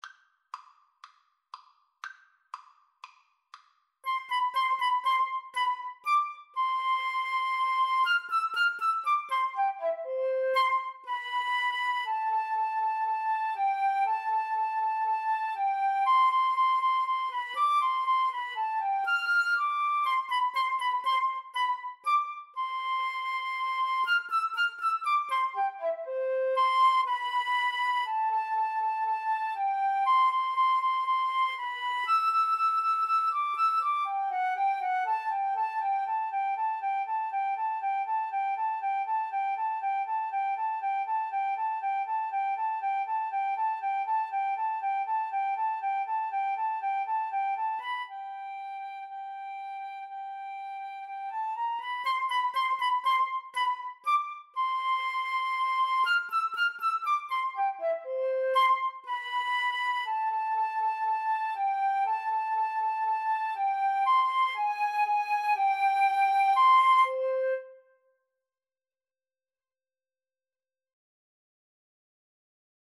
Play (or use space bar on your keyboard) Pause Music Playalong - Player 1 Accompaniment reset tempo print settings full screen
C major (Sounding Pitch) (View more C major Music for Flute Duet )
Allegro (View more music marked Allegro)
Classical (View more Classical Flute Duet Music)